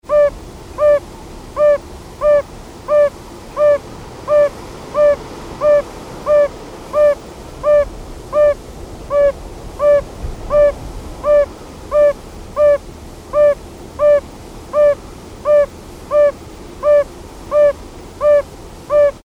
Audiodateien, nicht aus dem Schutzgebiet
Gelbbauchunke UB